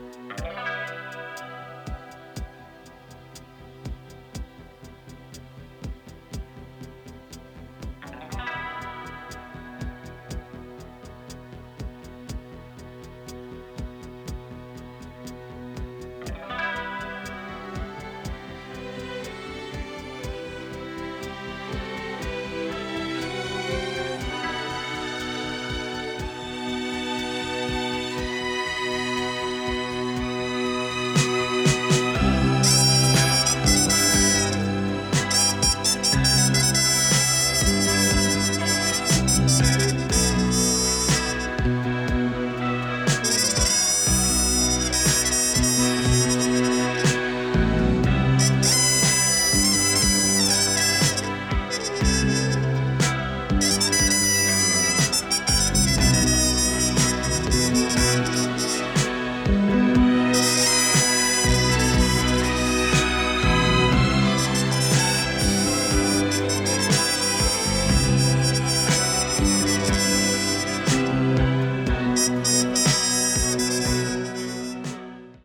in stereo and mint condition